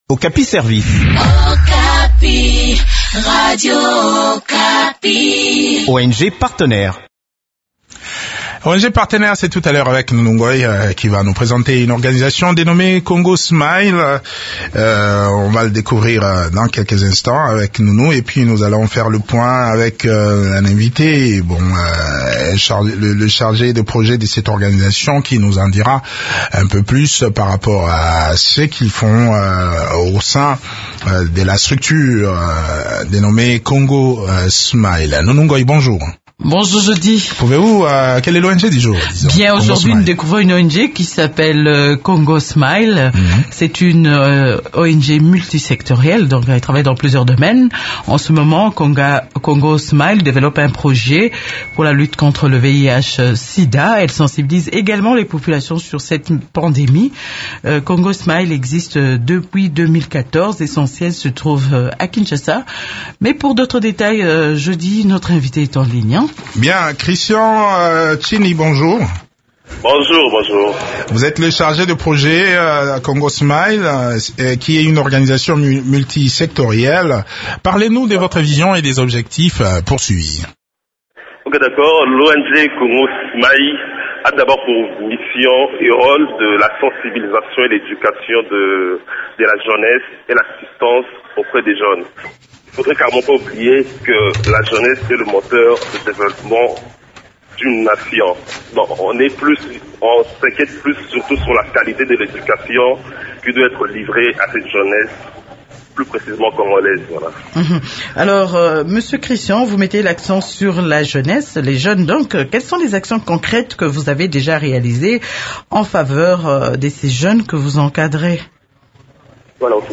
Le point sur les activités de cette structure dans cet entretein